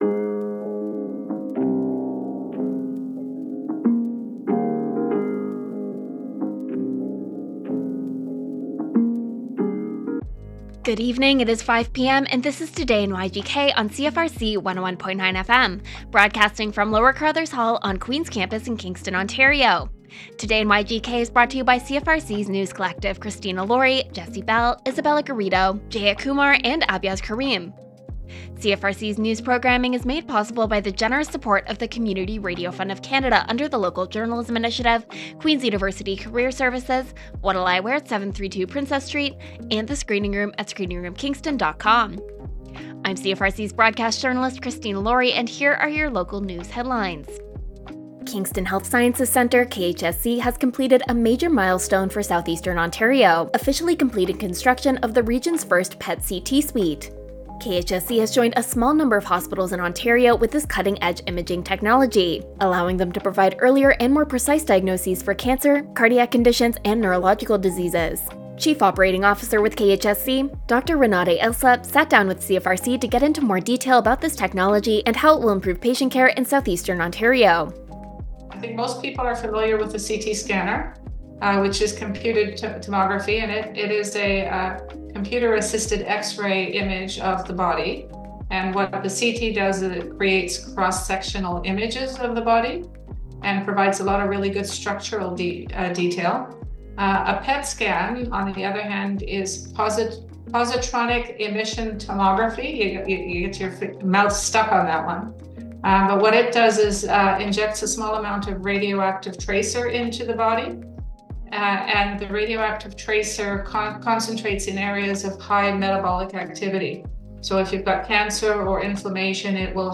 Local news with CFRC’s News Team.